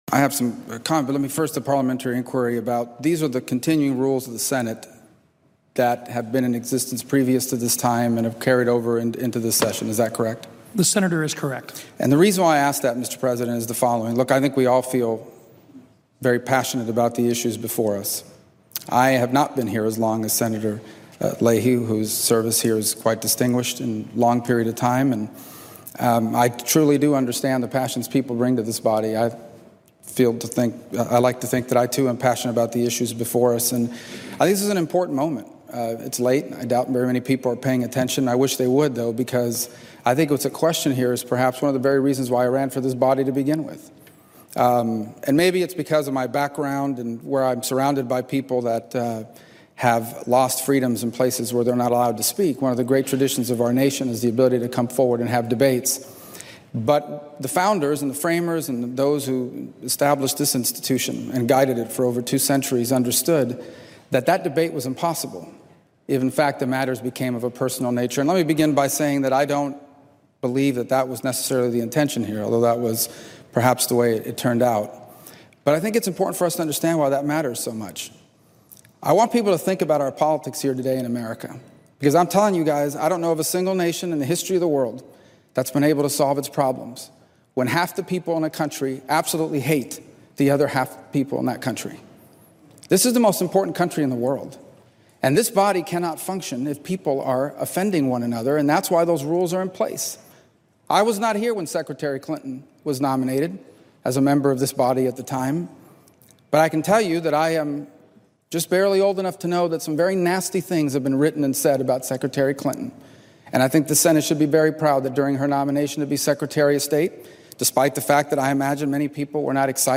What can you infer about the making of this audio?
Senate Floor Speech on Civility in the Senate delivered 7 February 2017, Washington, D.C.